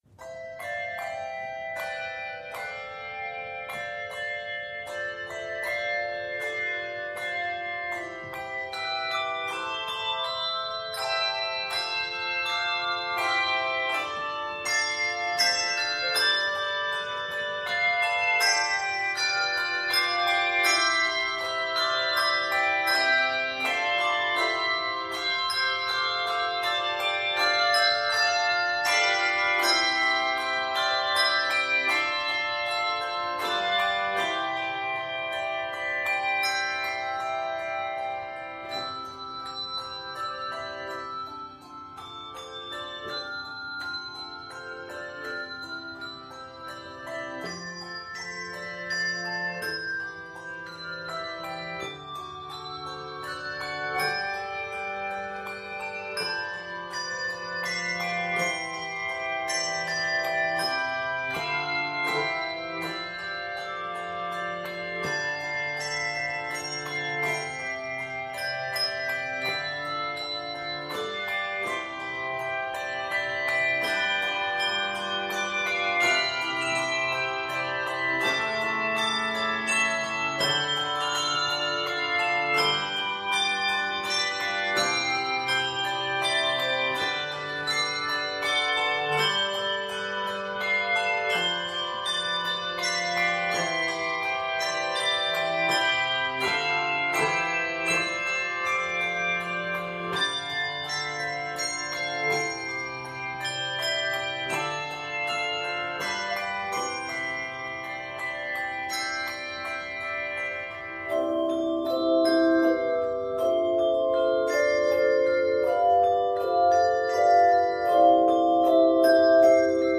It is scored in C Major.